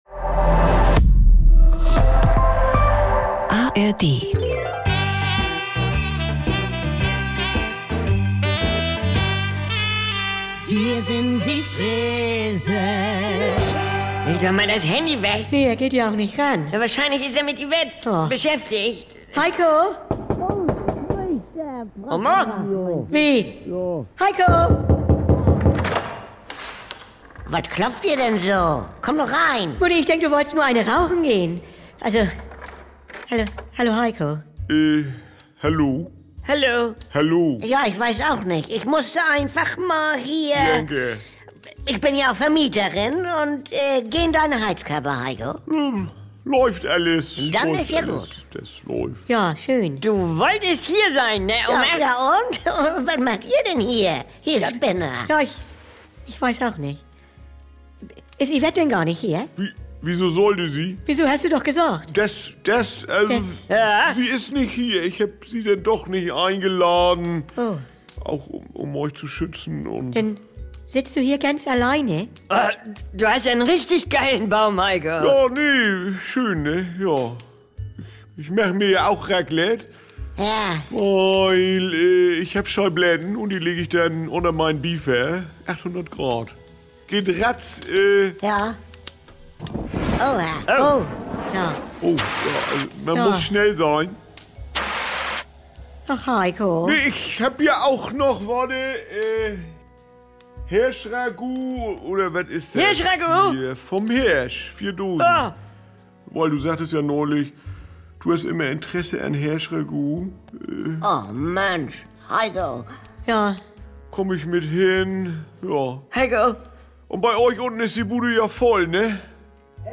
Fortsetzung: Heiko muss doch nicht alleine Weihnachten feiern. er gehört ja schließlich zur "Kernfamilie". Jederzeit und so oft ihr wollt: Die NDR 2 Kult-Comedy direkt aus dem Mehrgenerationen-Haushalt der Familie Freese.